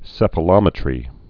(sĕfə-lŏmĭ-trē)